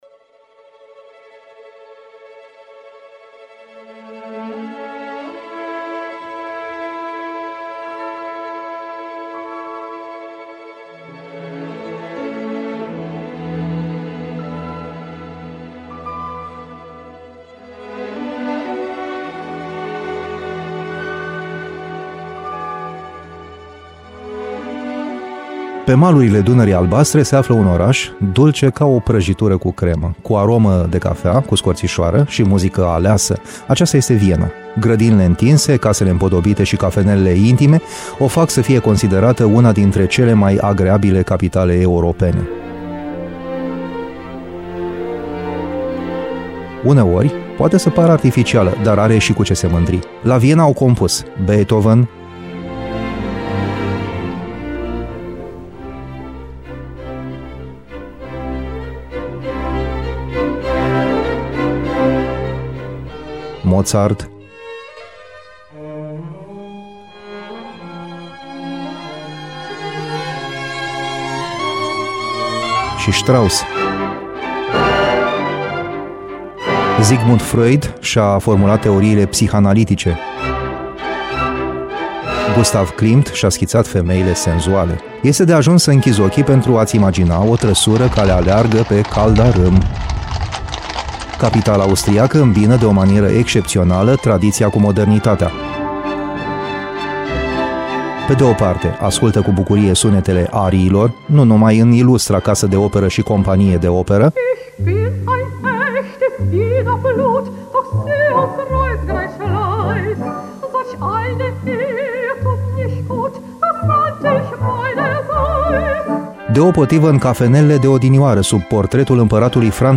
feature audio